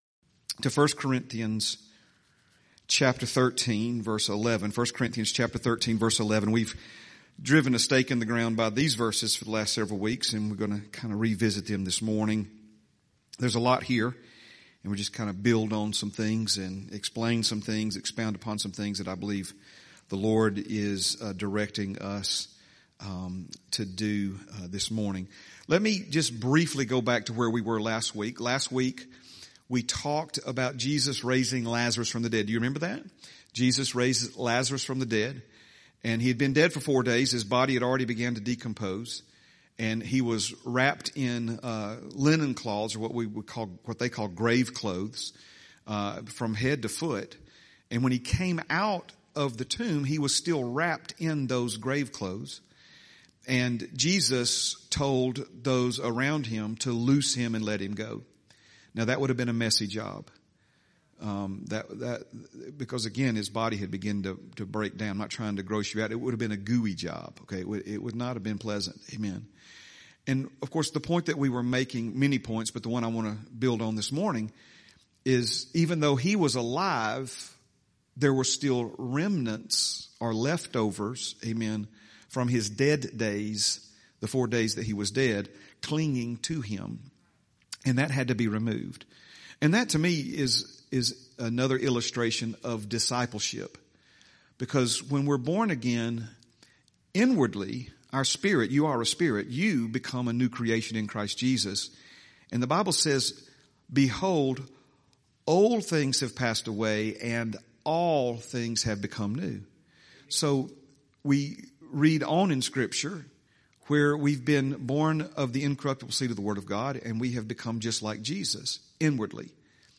10/12/25 Sunday - Sunday Morning Message